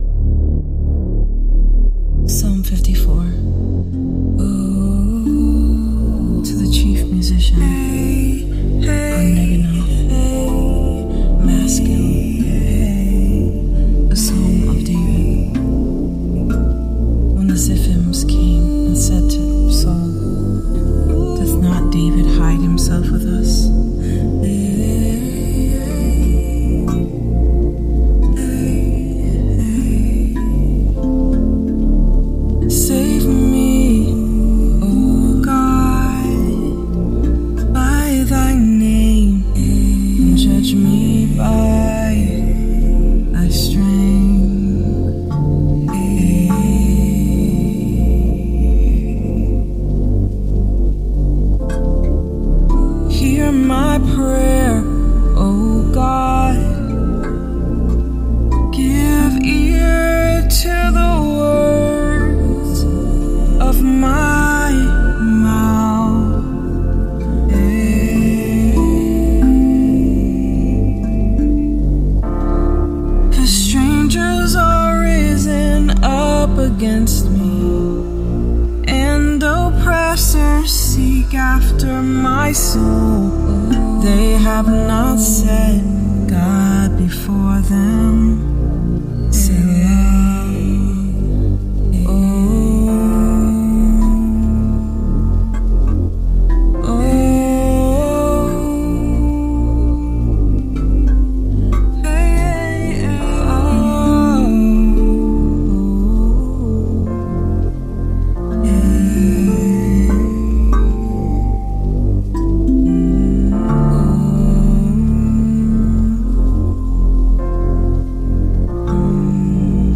Rav vast drum